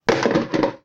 描述：从装满水的塑料壶里掉下来
标签： 下降 刮痧
声道立体声